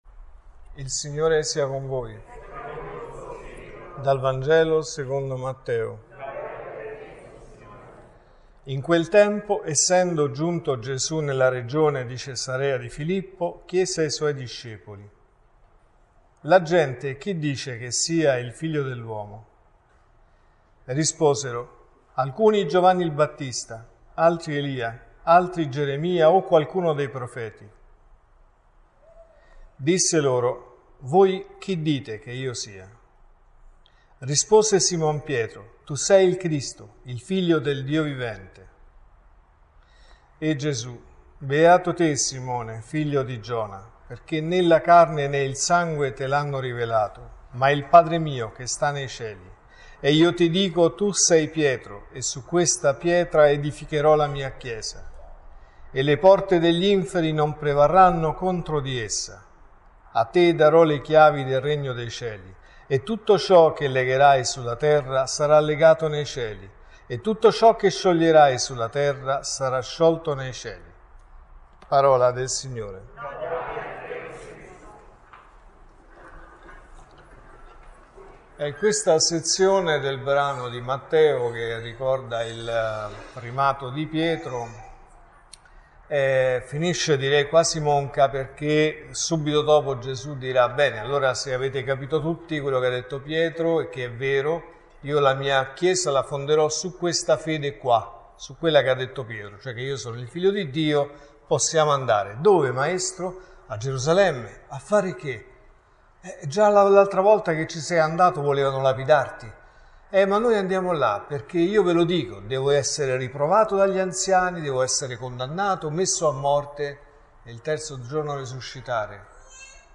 Tu sei Pietro, a te darò le chiavi del regno dei cieli.(Messa del mattino e della sera)